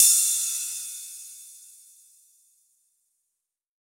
9RIDE1.wav